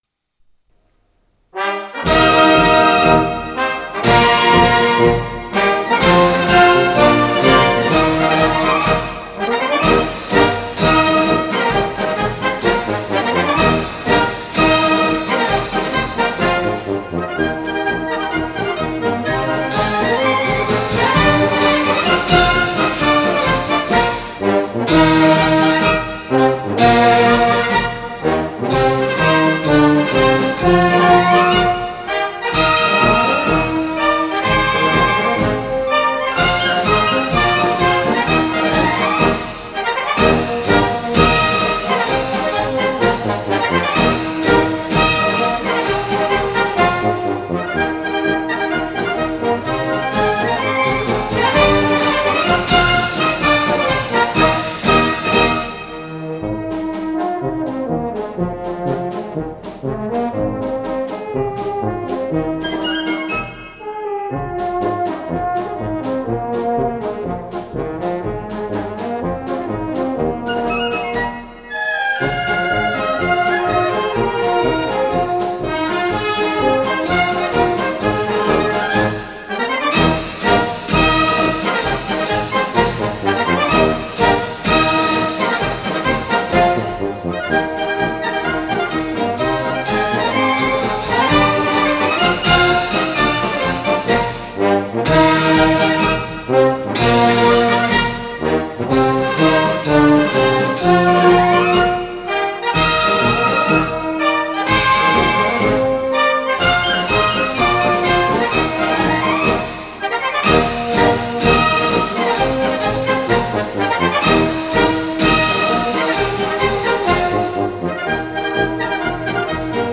5.3 MB 分类:作者:军乐团 1
欢迎进行曲.mp3